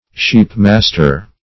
Search Result for " sheepmaster" : The Collaborative International Dictionary of English v.0.48: Sheepmaster \Sheep"mas`ter\, n. A keeper or feeder of sheep; also, an owner of sheep.